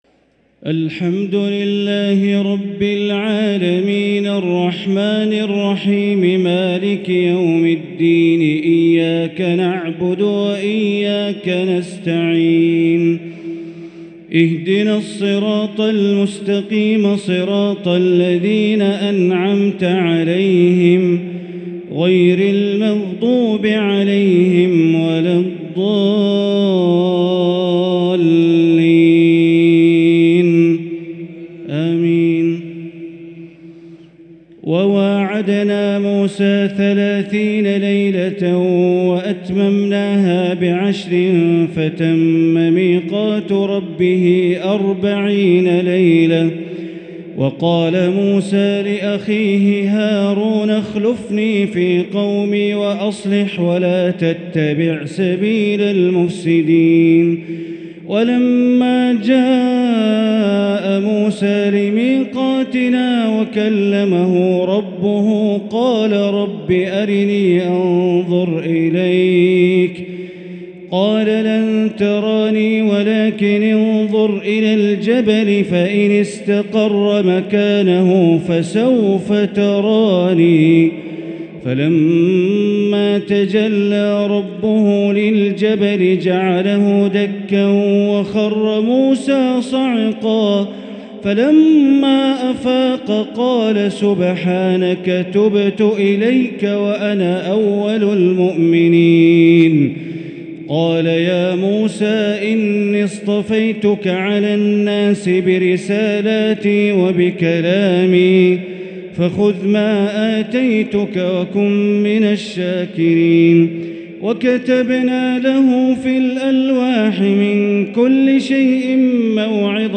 تراويح ليلة 12 رمضان 1444هـ من سورة الأعراف (142-200) |taraweeh 12st niqht ramadan Surah Al-A’raf 1444H > تراويح الحرم المكي عام 1444 🕋 > التراويح - تلاوات الحرمين